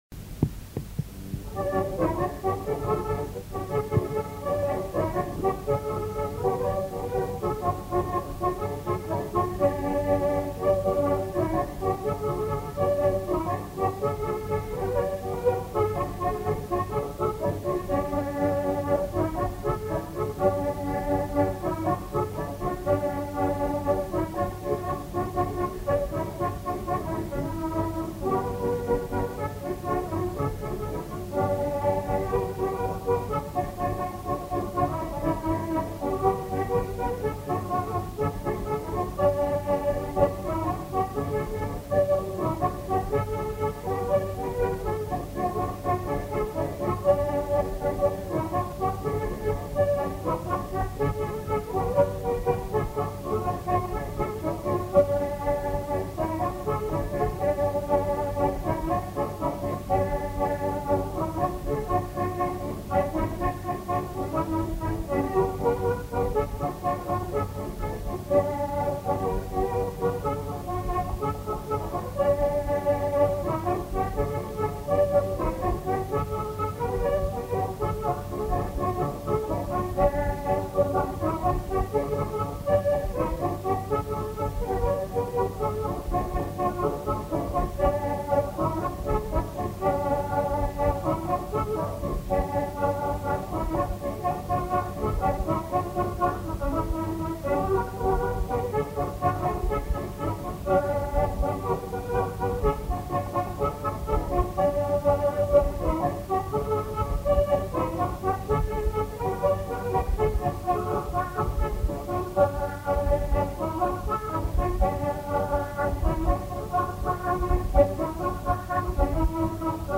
Instrumental. Accordéon diatonique.
Aire culturelle : Pays Gallo
Lieu : Monclar d'Agenais
Genre : morceau instrumental
Instrument de musique : accordéon diatonique